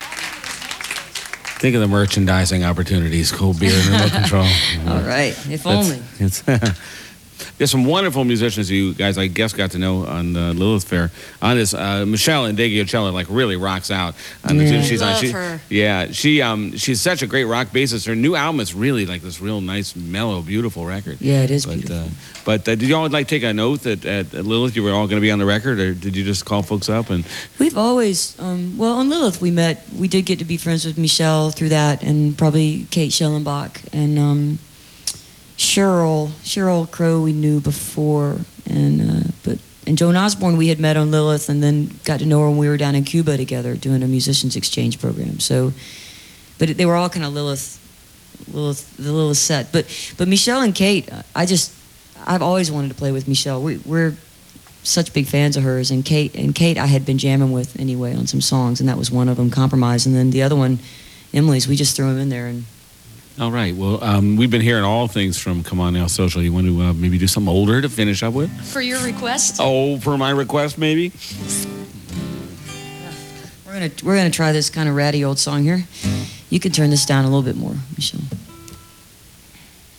lifeblood: bootlegs: 1999: 1999-10-05: world cafe recording session at inderay studios - philadelphia, pennsylvania (alternate source)
09. interview (1:20)